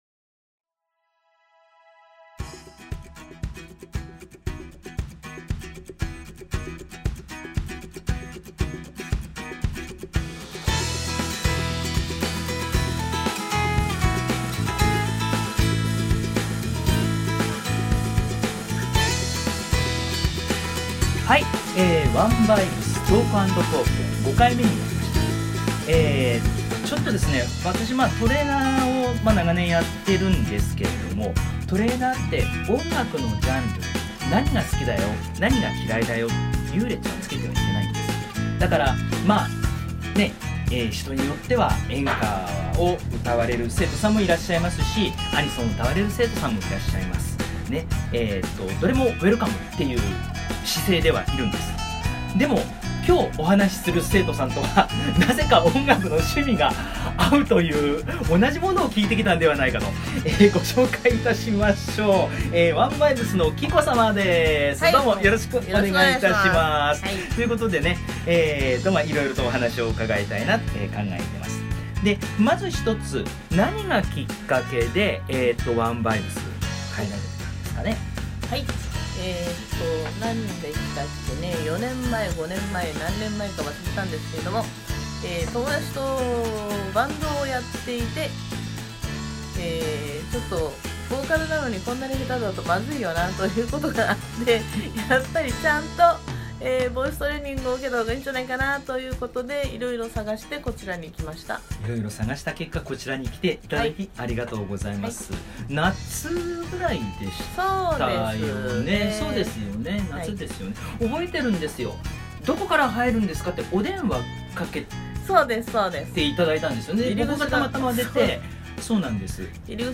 ハキハキとした話し方にテンポ感と躍動感があります。
実はこの生徒さんとの対談、昨年末のレッスンの後に行なったため、少々の●●●●●をガソリンとしながら語り進めたこと（リラックスした雰囲気の中で、という意味でとらえて頂けたら幸いです）を白状、、、あ、いや追加しておきましょう(笑)